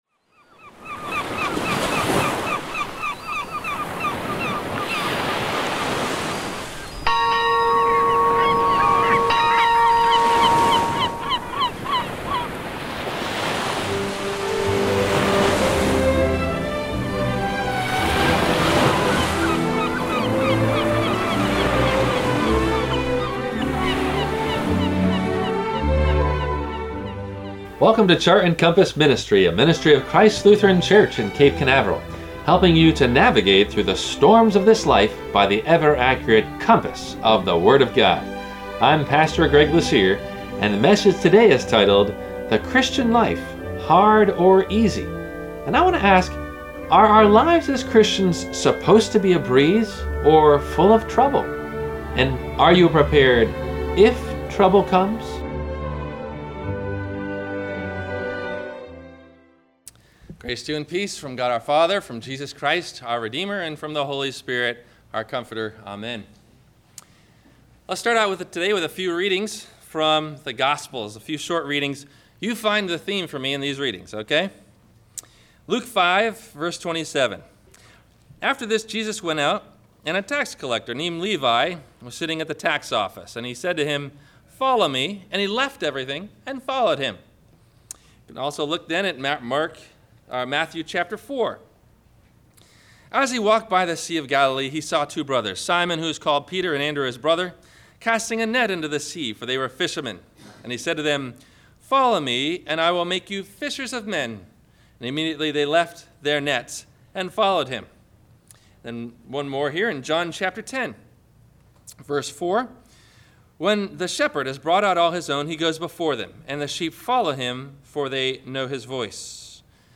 The Christian Life, Hard or Easy? – WMIE Radio Sermon – July 07 2014